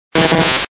Cri d'Élekid dans Pokémon Diamant et Perle.